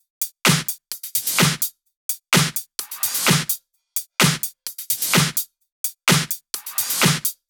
VFH2 128BPM Tron Quarter Kit 5.wav